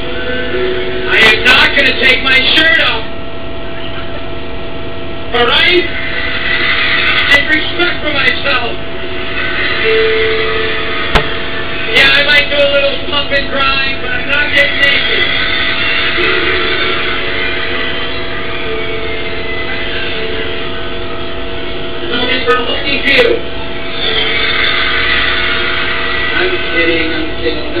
Here it is THE wav from the show: